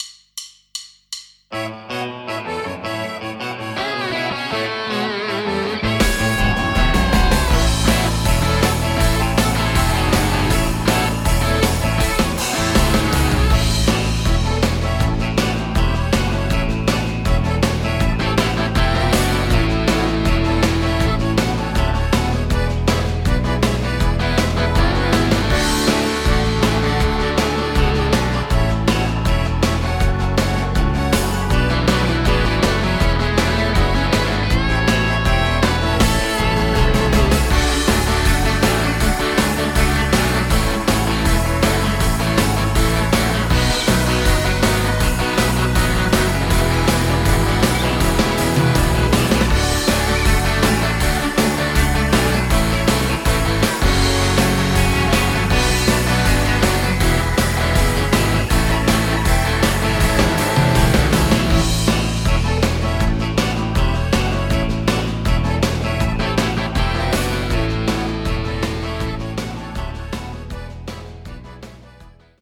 Instrumental, Karaoke